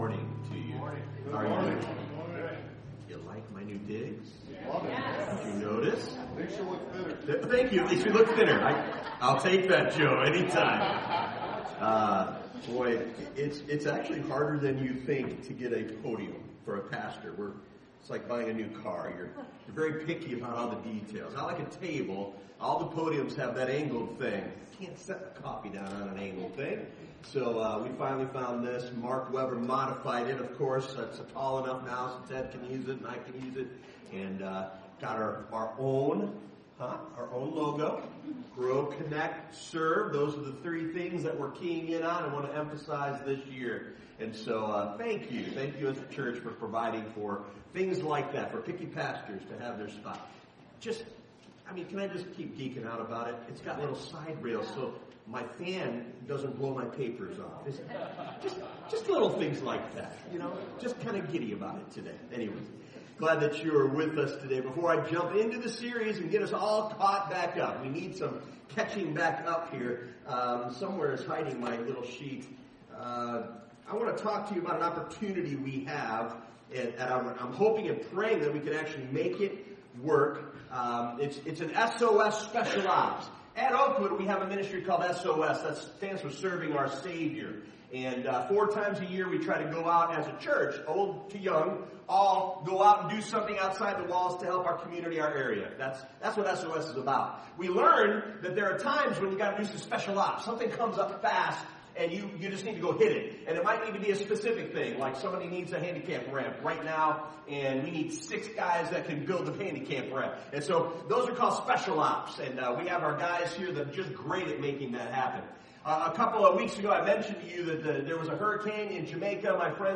From this series Current Sermon Obeying What Jesus Said...